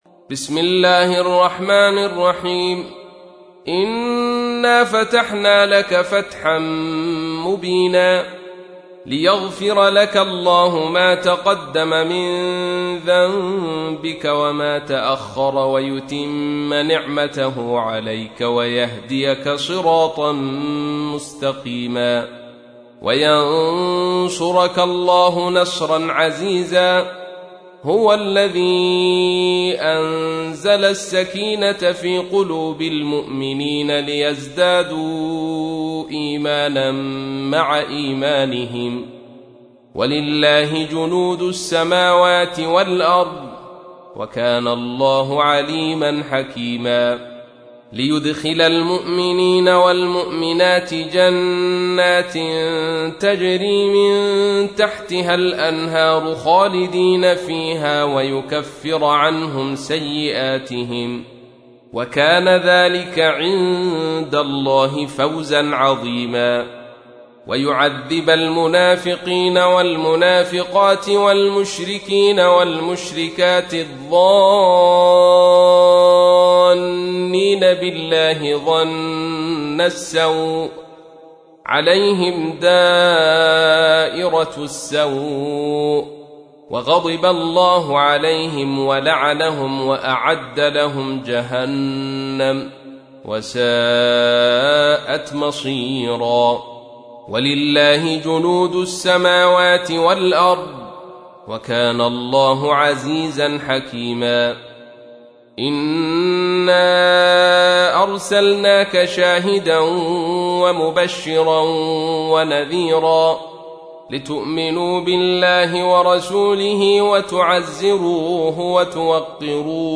تحميل : 48. سورة الفتح / القارئ عبد الرشيد صوفي / القرآن الكريم / موقع يا حسين